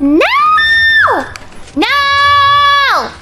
Worms speechbanks
Whatthe.wav